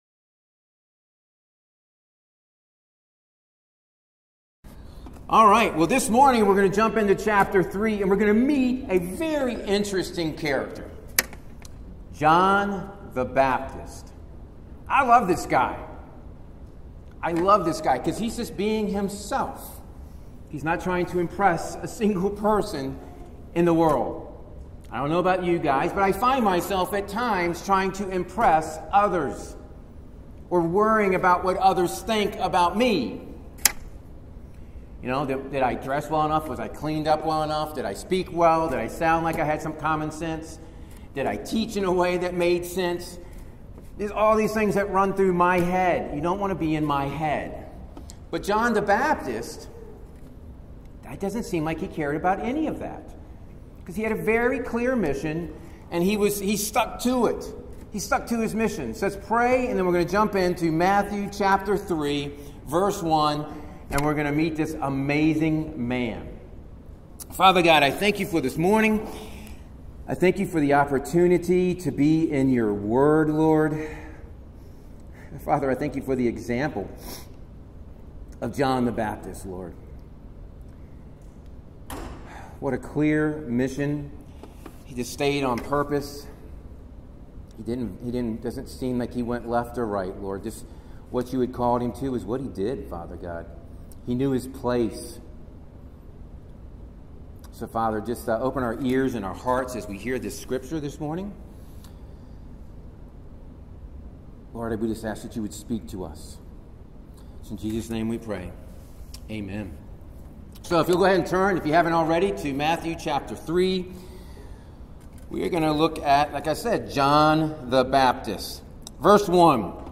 SERMONS | Proclaim Christ Church | The Gospel of Matthew